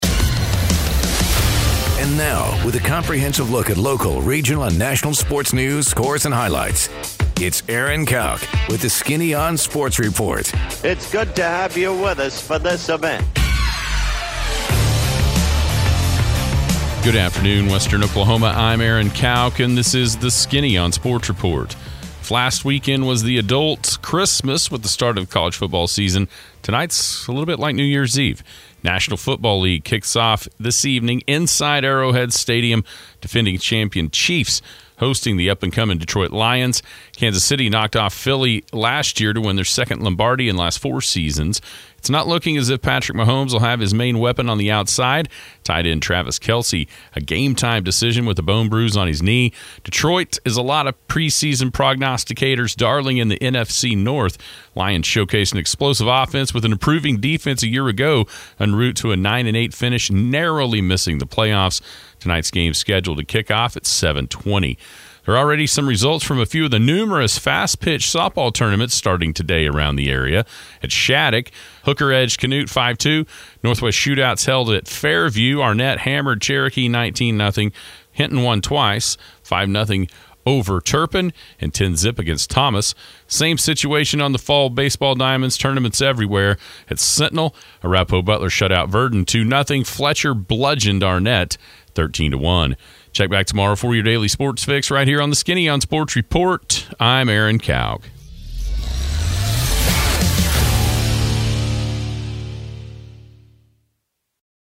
The NFL season begins with an exciting matchup between the Chiefs and the Lions. Get all the details and updates in this Skinny on Sports report.